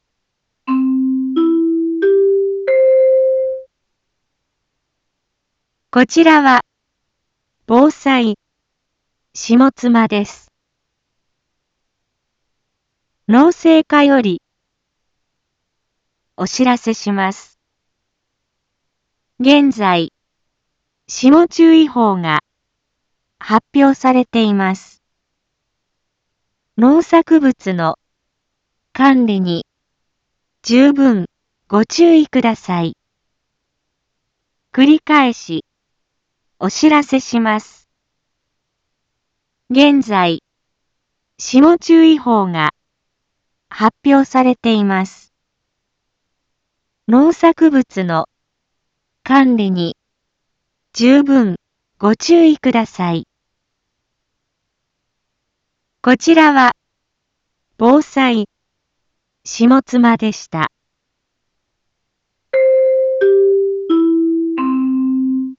一般放送情報
Back Home 一般放送情報 音声放送 再生 一般放送情報 登録日時：2021-04-14 18:01:09 タイトル：霜注意報 インフォメーション：こちらは防災下妻です。